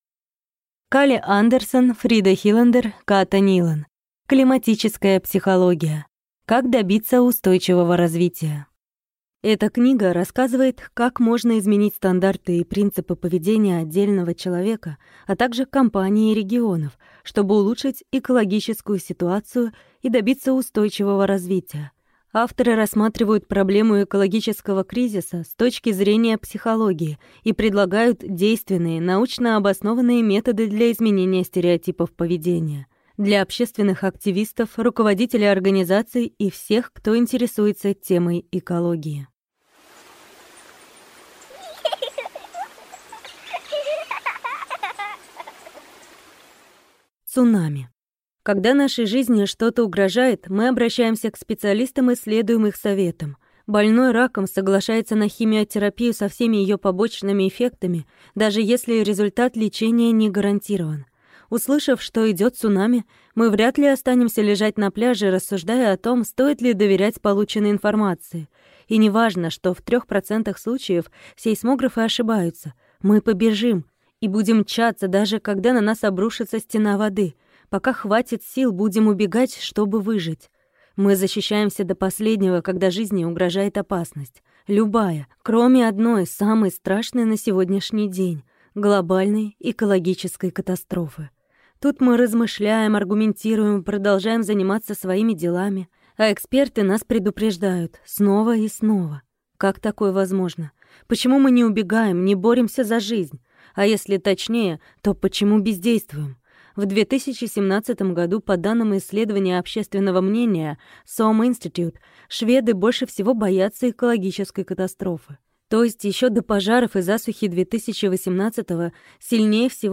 Аудиокнига Климатическая психология. Как добиться устойчивого развития | Библиотека аудиокниг